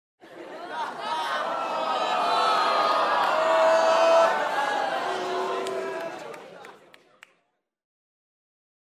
На этой странице собраны звуки, которые ассоциируются с чувством позора: смущенное бормотание, нервный смешок, вздохи разочарования.
8. Ооо, ууу